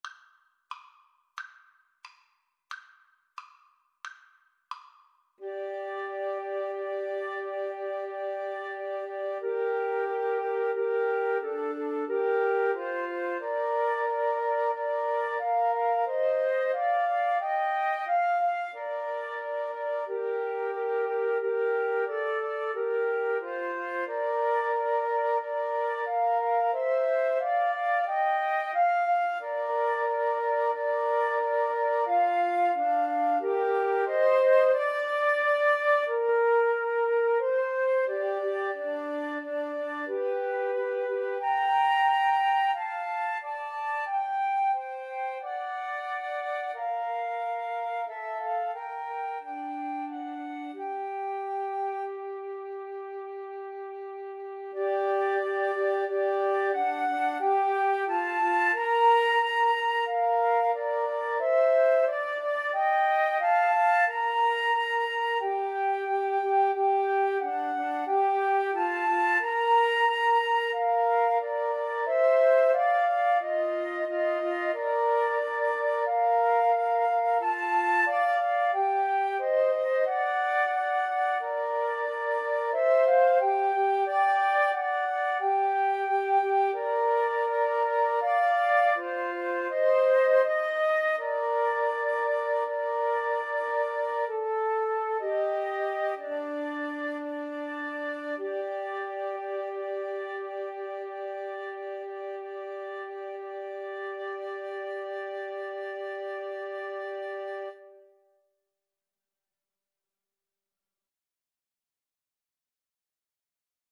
Flute 1Flute 2Flute 3
2/4 (View more 2/4 Music)
=90 Allegretto, ma un poco lento
Classical (View more Classical Flute Trio Music)